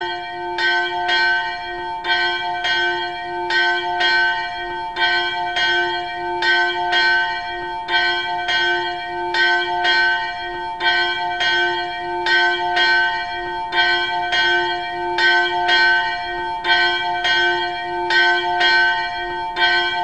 Zvony - umíráček